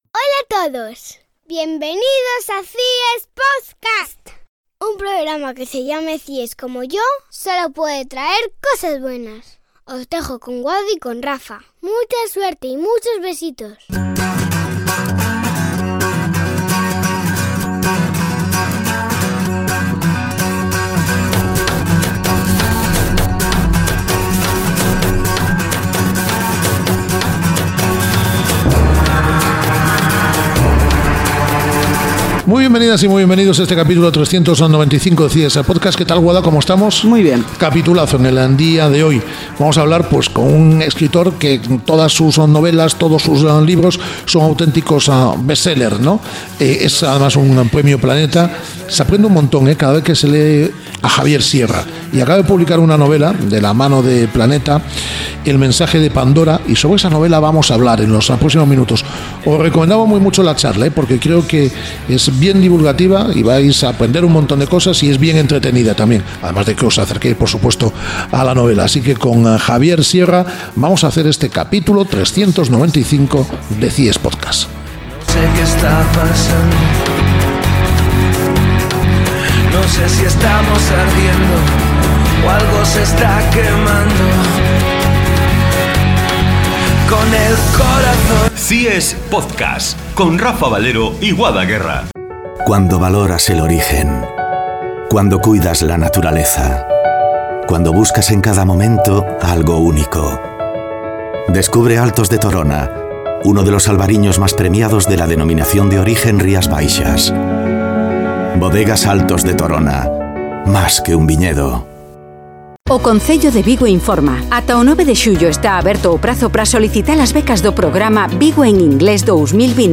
Charla muy recomendable con Javier Sierra, sobre su última novela ‘El mensaje de Pandora’, editada por Planeta.